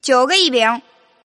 Index of /client/common_mahjong_tianjin/mahjonghntj/update/1304/res/sfx/tianjin/woman/